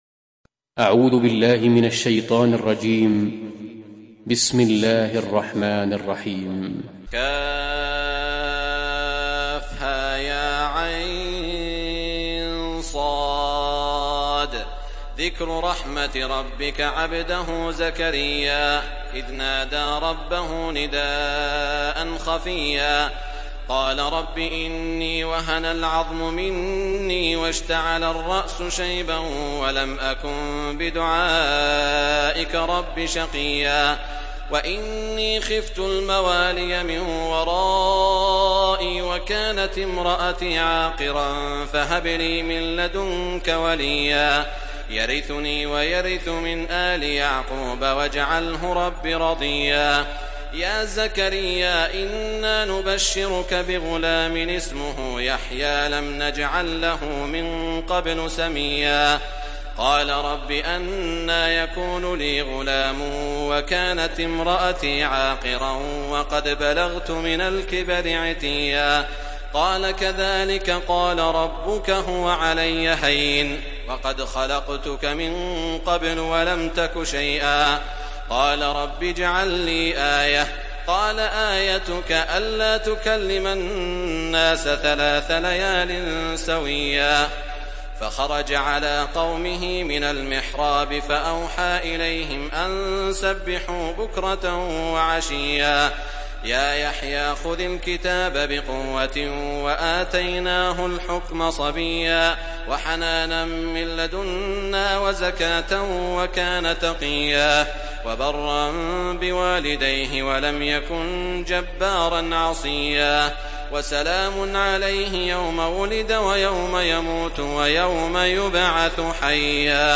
تحميل سورة مريم mp3 بصوت سعود الشريم برواية حفص عن عاصم, تحميل استماع القرآن الكريم على الجوال mp3 كاملا بروابط مباشرة وسريعة